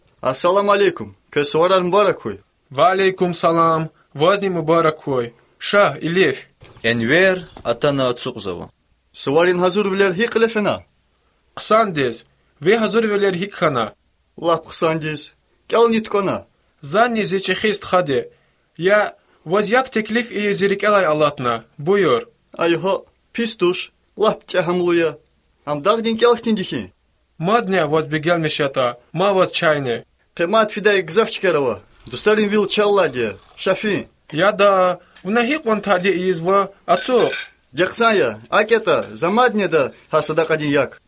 6 January 2013 at 11:23 am The only thing I understand are the greetings in the beginning.